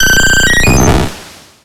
Cri d'Aquali dans Pokémon X et Y.